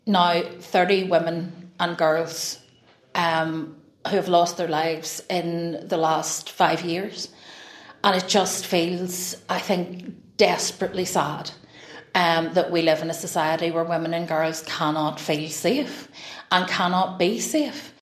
Naomi Long says things have to change: